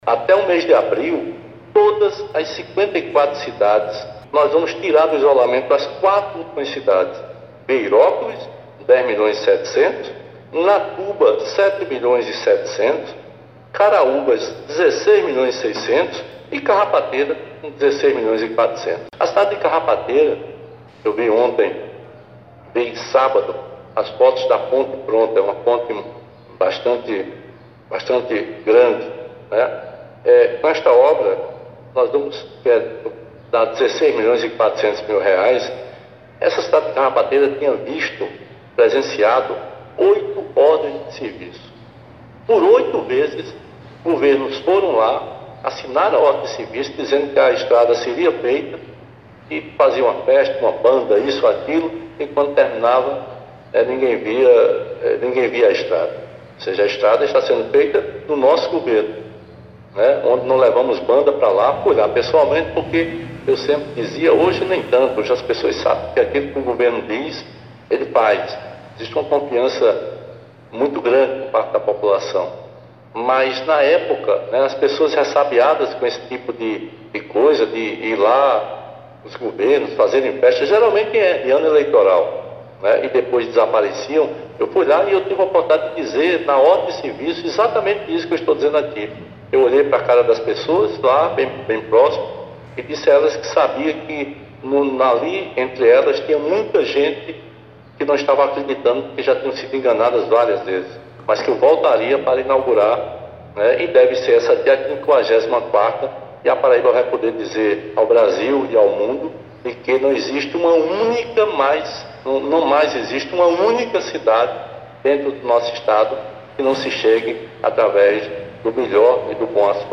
A entrevista repercutida pelo portal Radar Sertanejo foi gerada pela Rádio Tabajara, durante o Programa Fala Governador, desta segunda-feira.
Confira o áudio da fala do governador, abaixo: